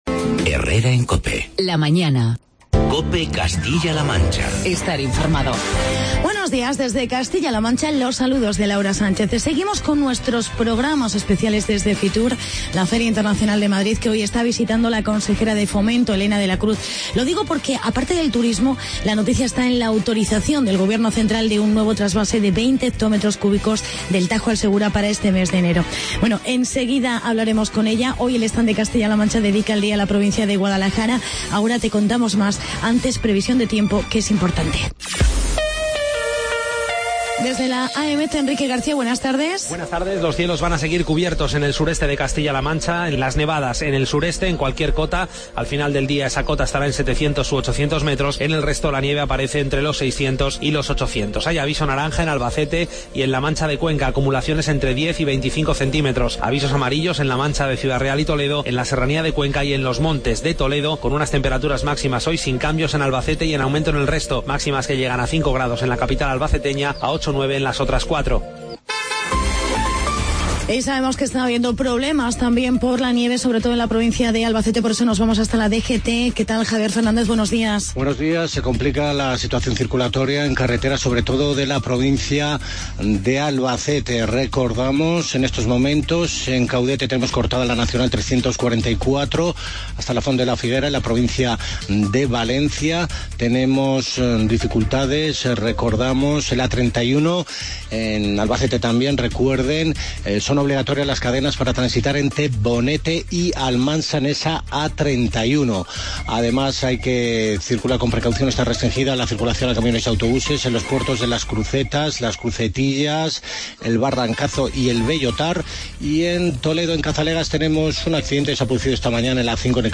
Especial Fitur con entrevista a la consejera de Fomento, Elena de la Cruz.